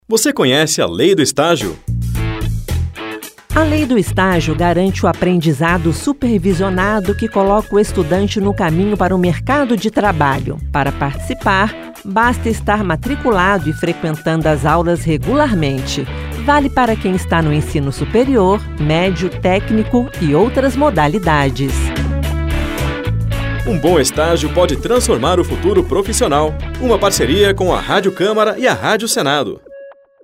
Esta campanha da Rádio Câmara e da Rádio Senado traz cinco spots de 30 segundos sobre a Lei do Estágio: seus direitos, obrigações e os principais pontos da lei.